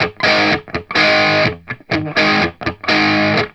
RIFF1-125GS.wav